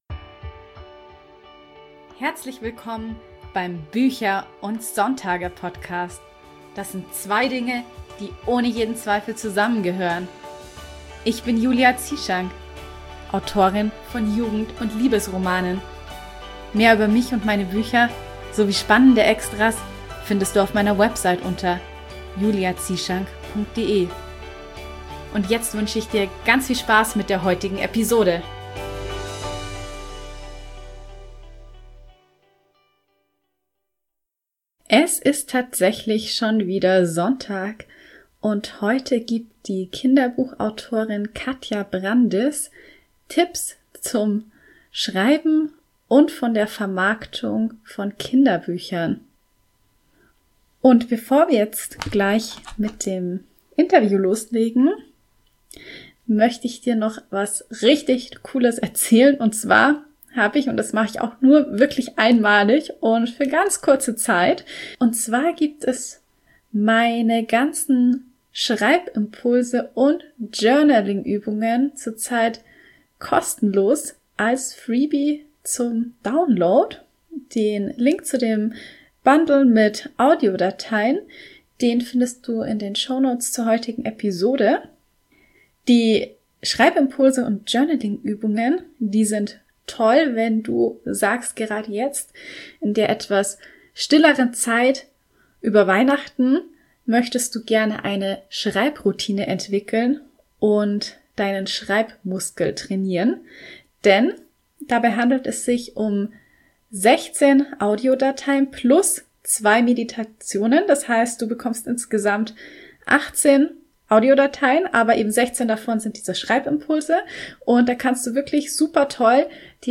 Wie es für die beiden war, nach so vielen Jahren in die Tintenwelt zurückzukehren und welche Figuren ihnen am meisten Spaß bereitet haben, erzählen sie im Interview.